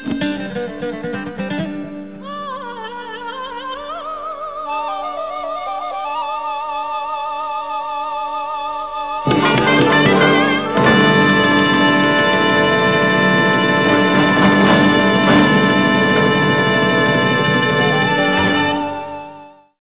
requiem